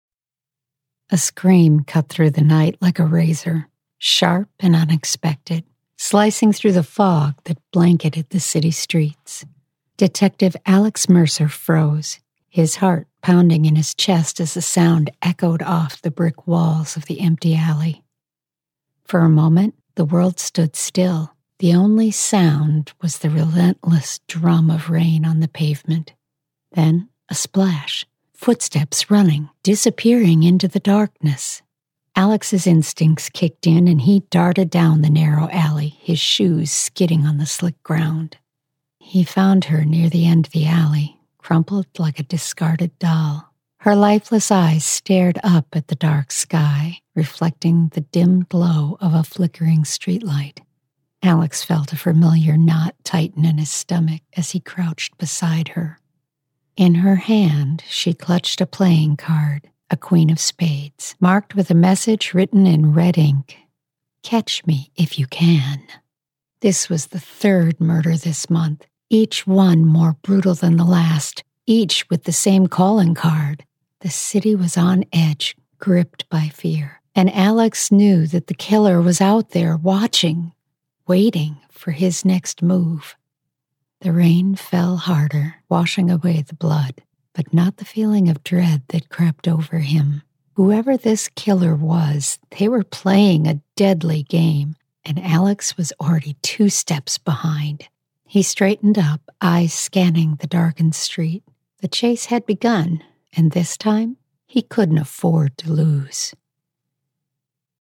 Audiobook Narration
American Midwest, American-Southern (genl), Irish-American
Middle Aged